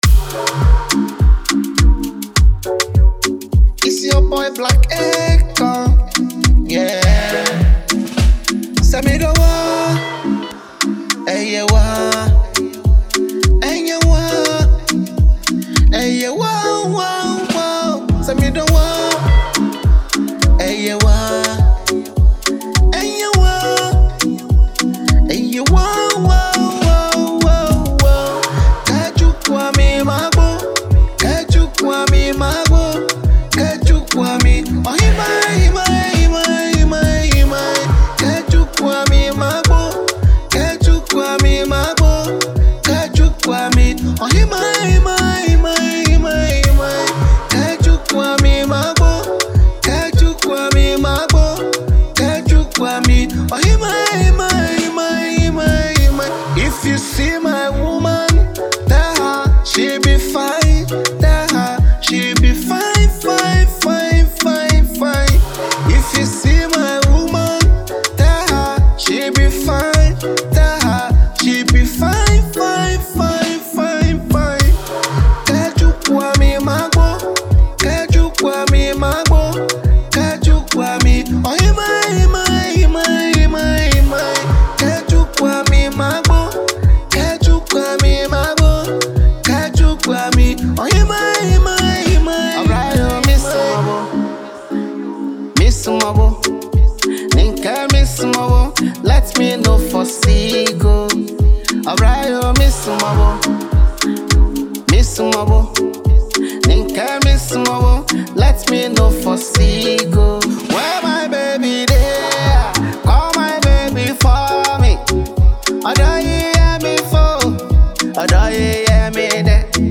love song
With its poignant lyrics and enchanting melody
soulful delivery